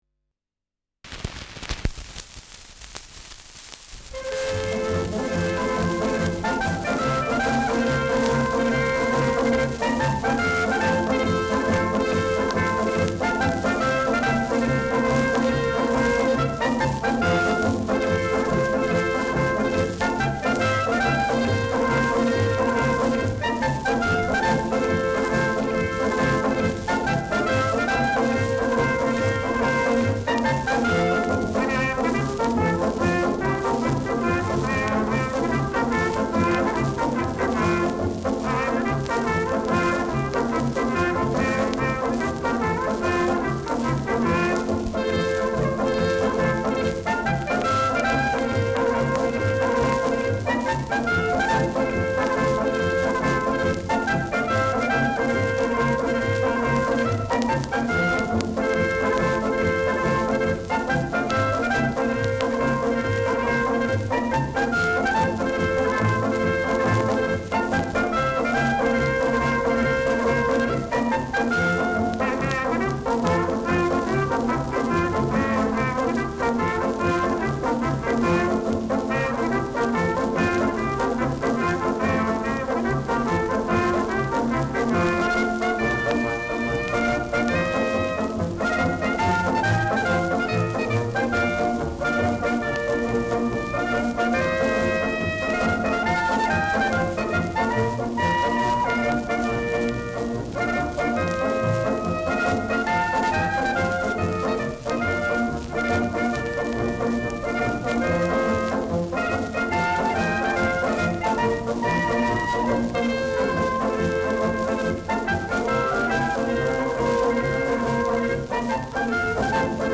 Schellackplatte
[Berlin] (Aufnahmeort)